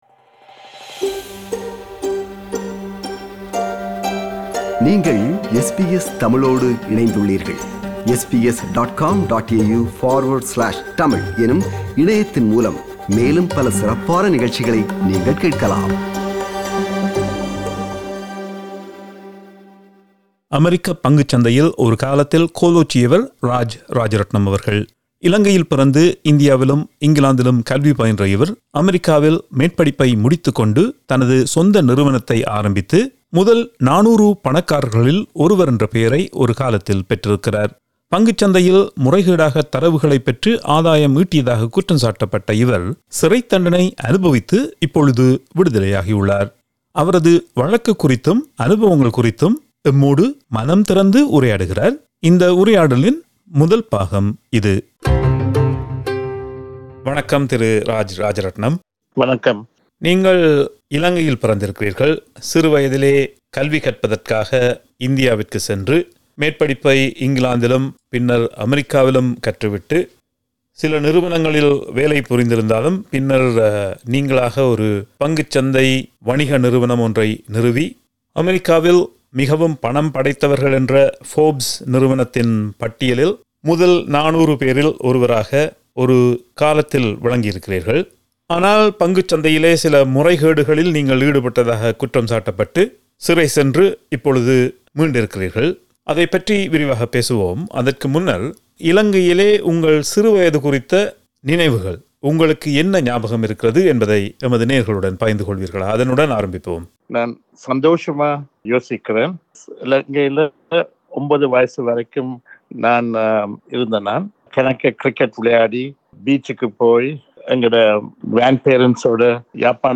This is the first of the two-part interview.